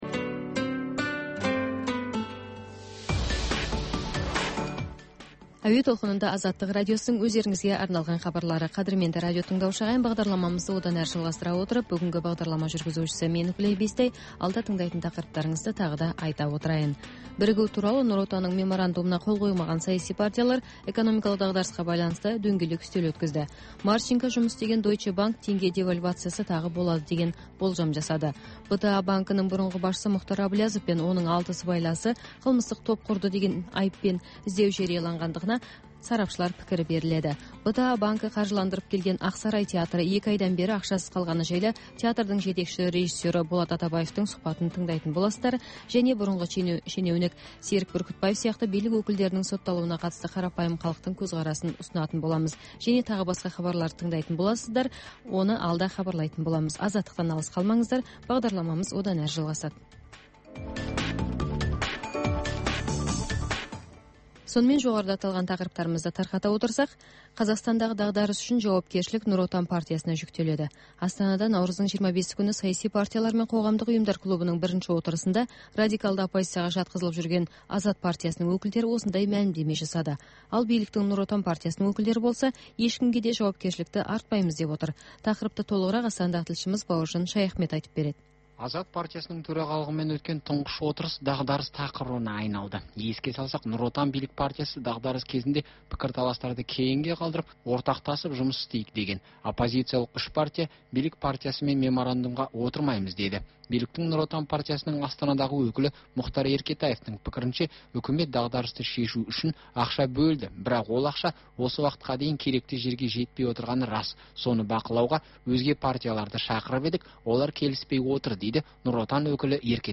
Бүгінгі күннің өзекті мәселесі, пікірталас, оқиға ортасынан алынған репортаж, қазақстандық және халықаралық сарапшылар пікірі, баспасөзге шолу.